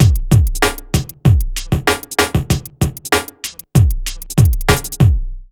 3RB96BEAT2-R.wav